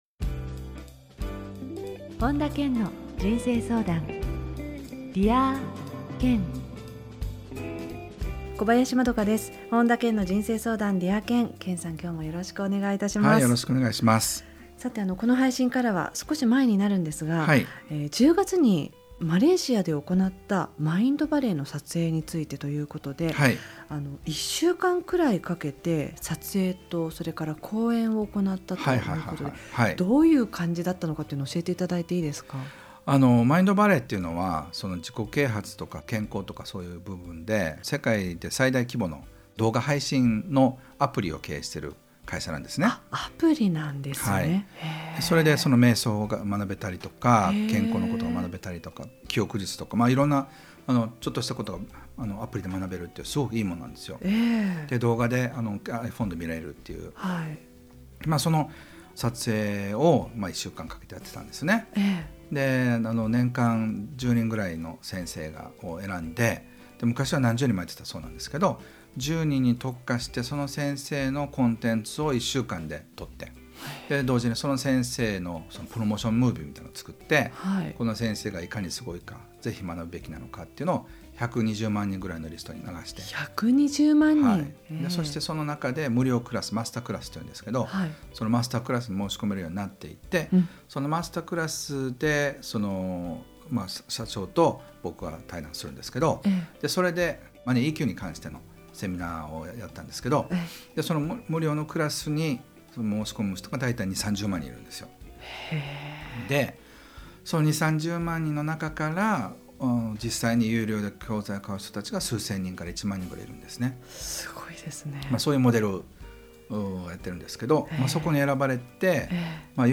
今回は「自分の潜在意識に気づく方法」をテーマに、本田健のラジオミニセミナーをお届...